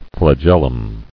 [fla·gel·lum]